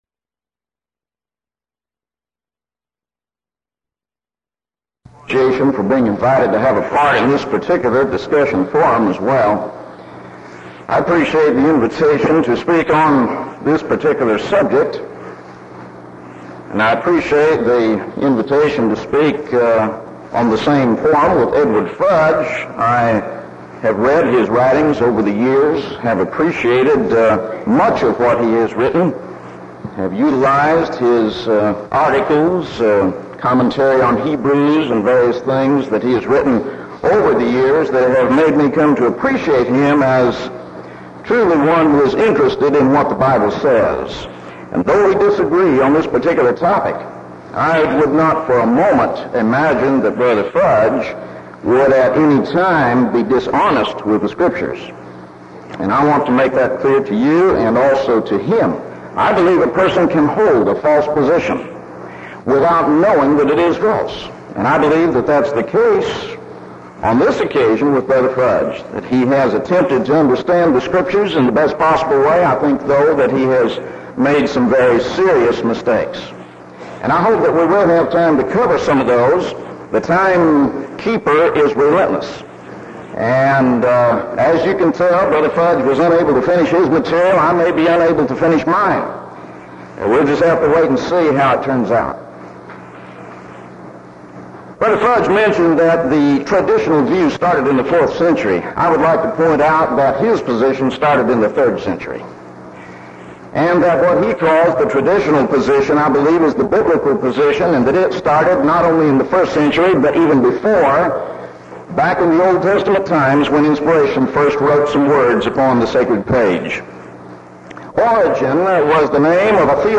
Event: 1984 Denton Lectures Theme/Title: Studies in the Book of Revelation